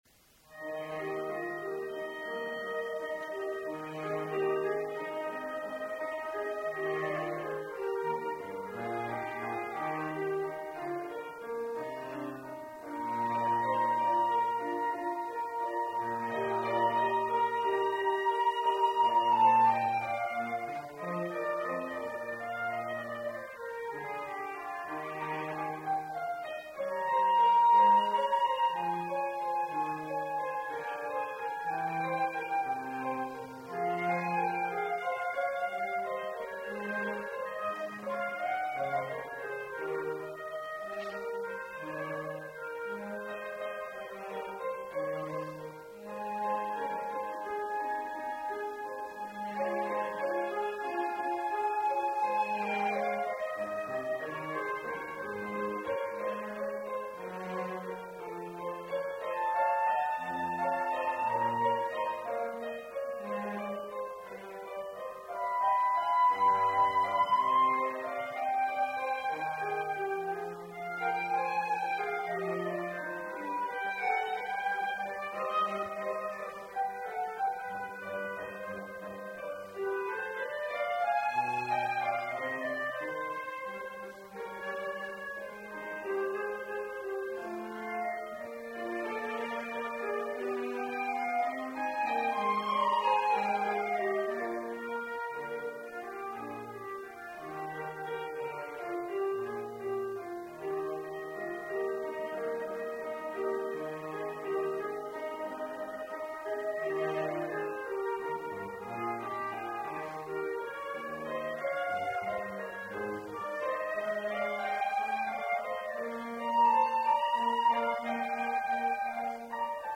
FACULTY RECITAL
Grant Recital Hall
Adagio                                                                       Allegro moderato